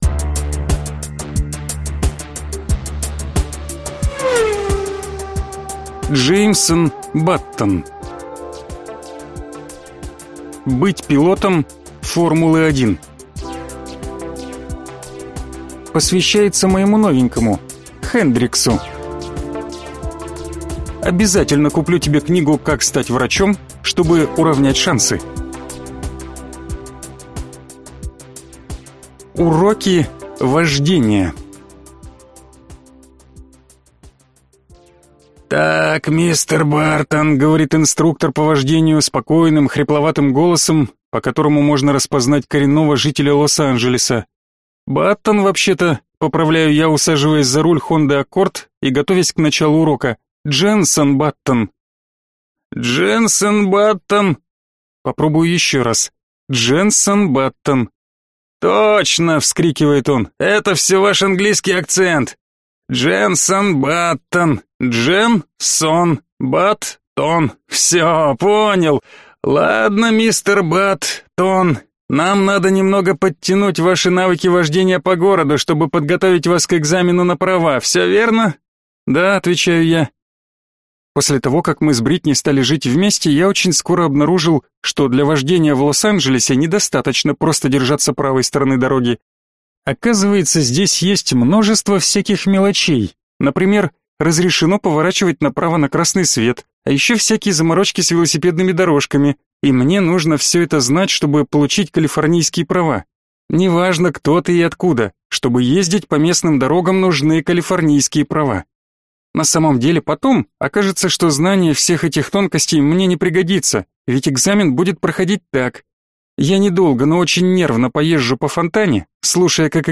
Аудиокнига Быть пилотом «Формулы-1» | Библиотека аудиокниг